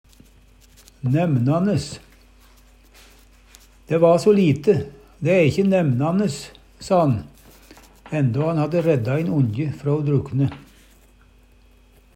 nemnan'es (ikkje nemnan'es) - Numedalsmål (en-US)